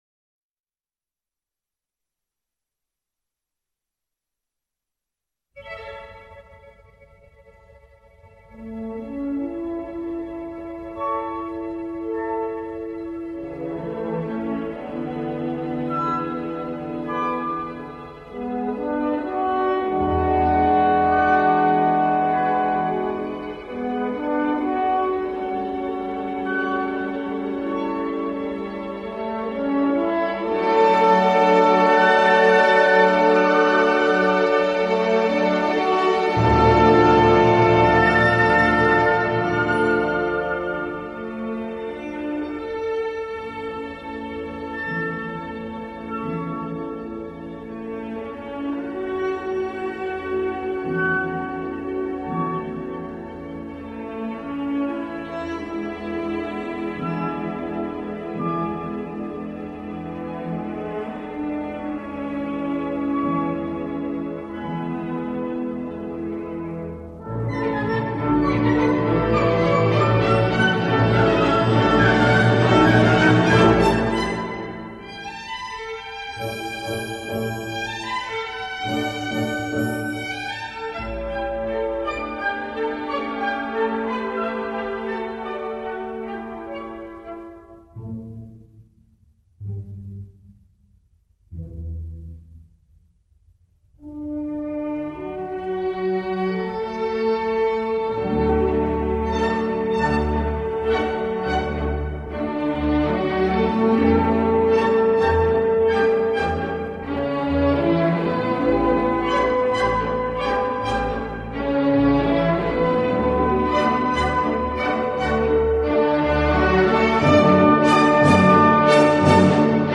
Есть но без хора